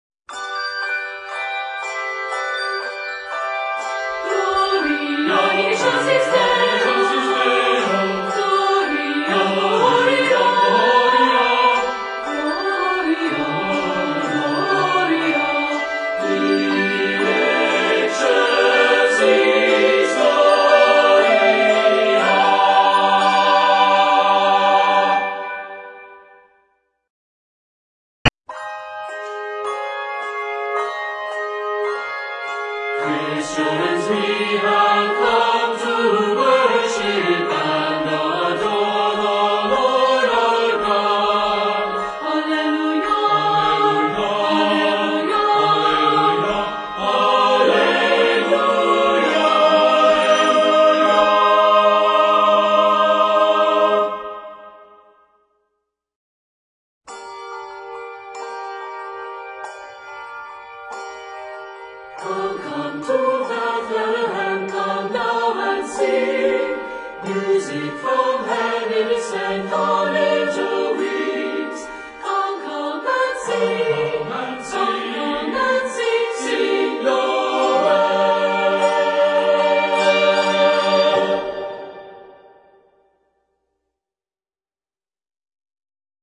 festive opening sentences for SATB and 2 octaves of bells